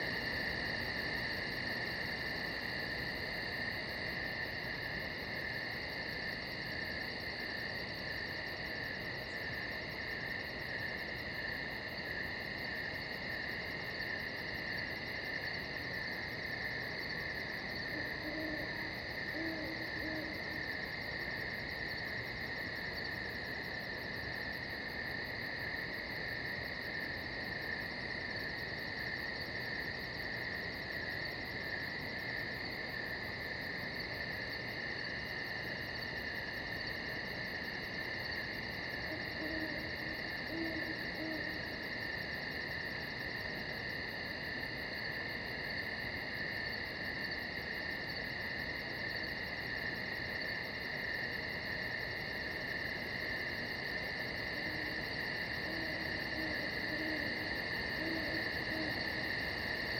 BGS Loops / Interior Night